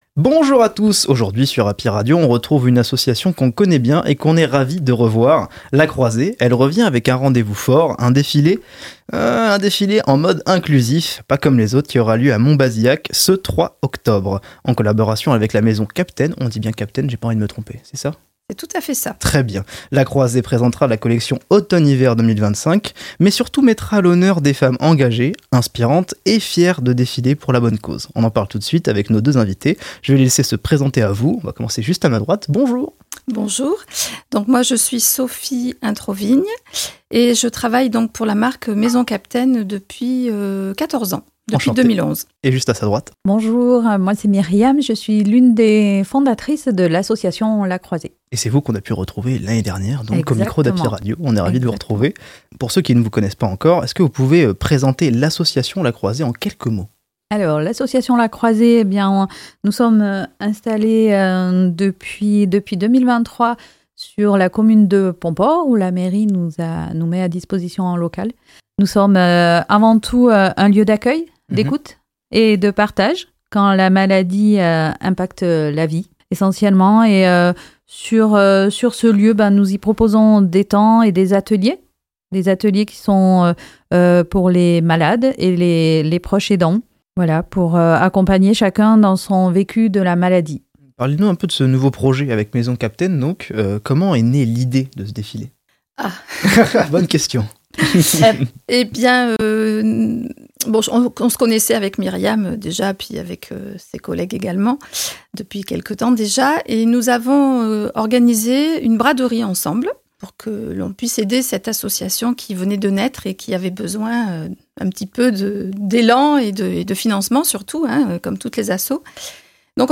Les interviews Happy Radio – La Croisée